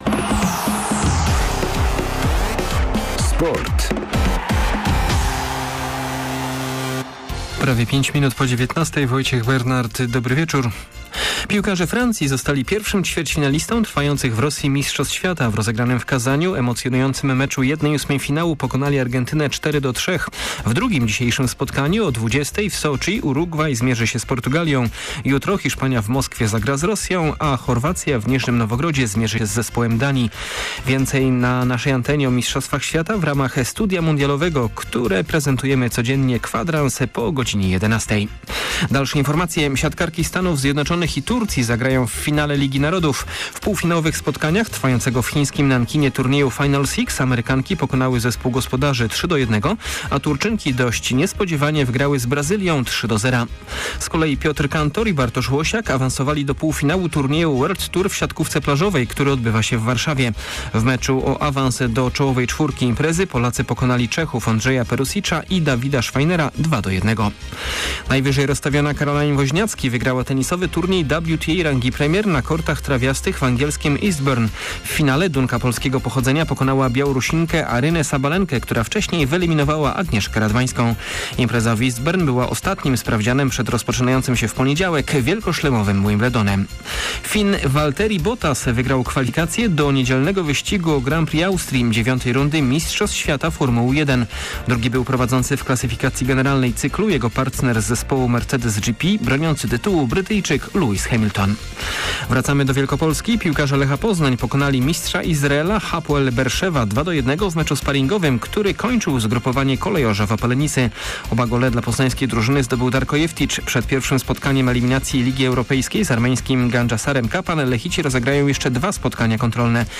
30.06 serwis sportowy godz. 19:05